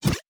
Tab Select 10.wav